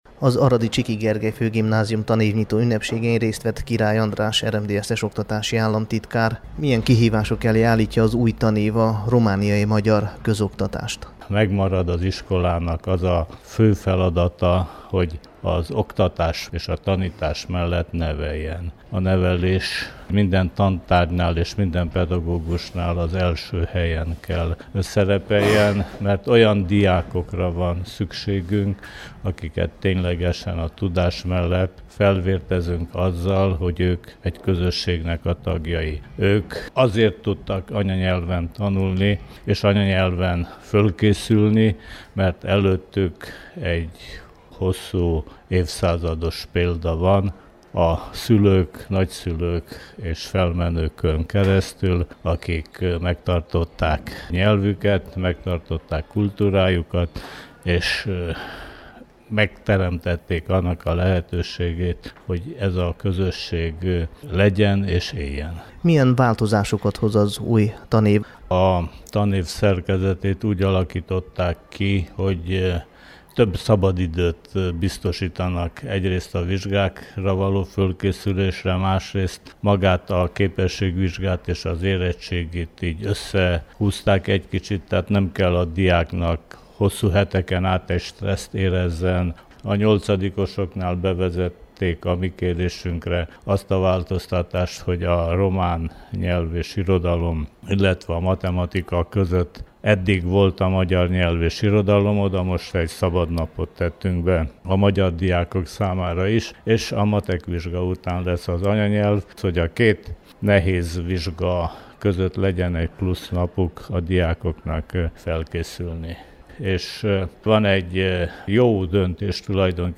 Az oktatás mellett az iskolák egyik legfontosabb feladata a nevelés, hogy a magyar diákok a magyar közösség felelős tagjaivá váljanak – jelentette ki Király András RMDSZ-es oktatási államtitkár Aradon, a Csiky Gergely Főgimnázium tanévnyitó ünnepségén.